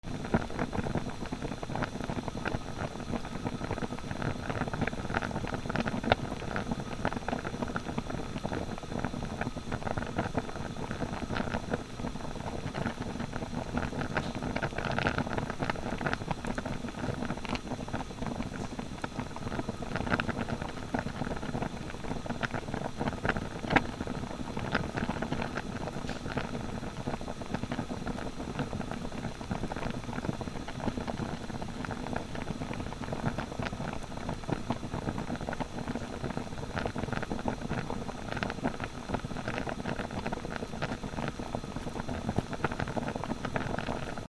なべ 沸騰